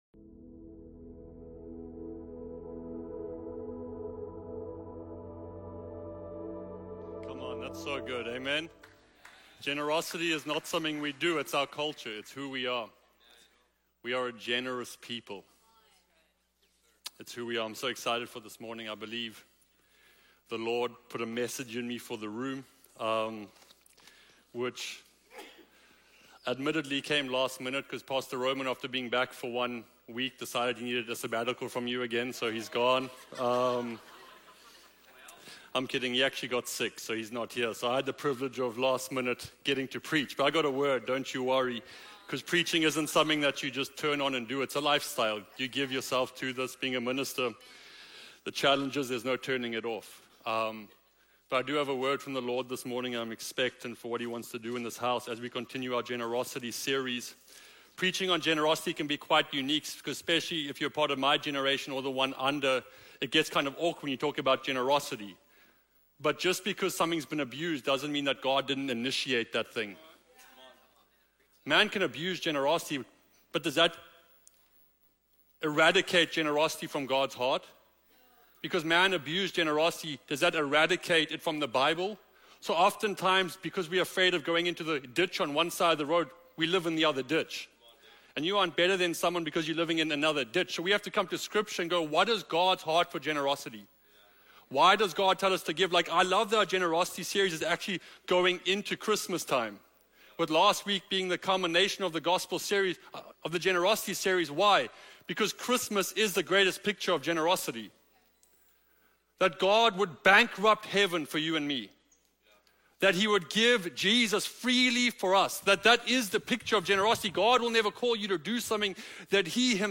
A collection of our Sunday Messages from Kingdom Movement. This sermon is Part 3 of our generosity series.